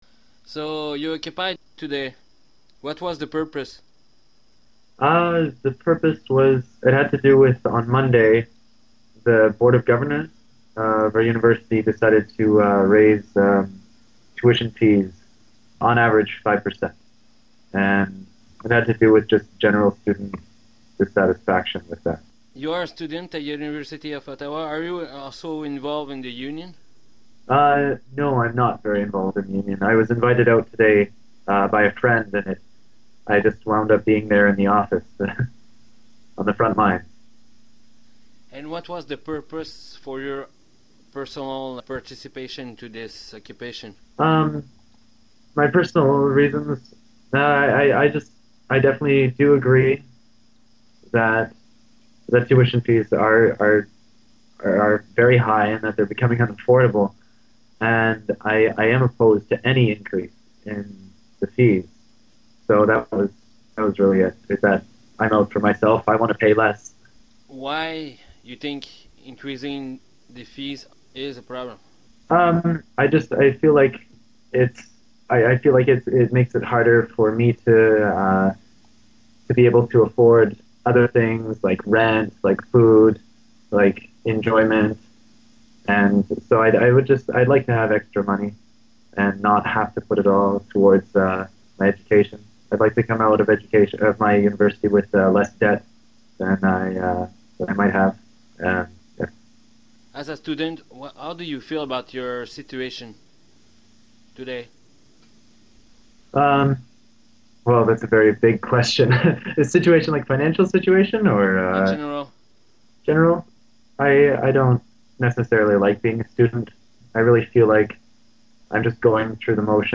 occupying University of Ottawa Administrative Offices november 24th 2010